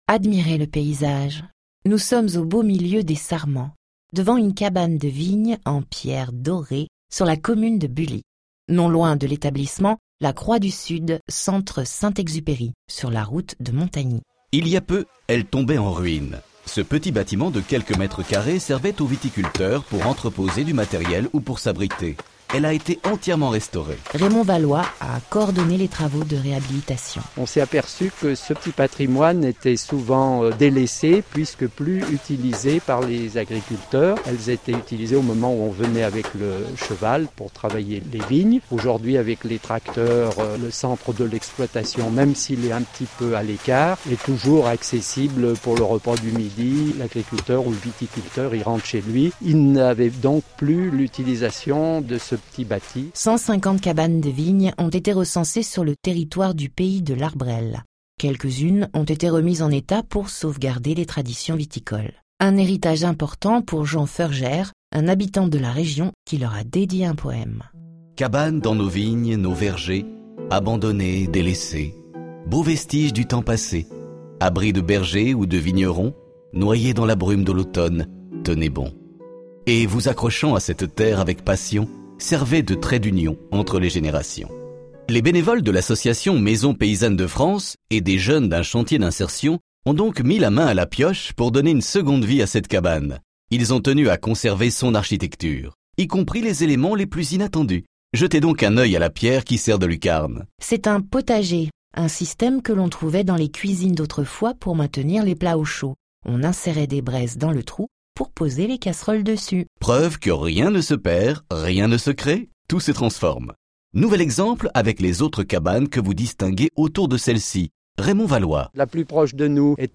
6 circuits audioguidés (l'histoire, les châteaux, le folflore, l'architecture, les paysages etc.) sont disponibles gratuitement sur simple inscription.
visite audio de Bully.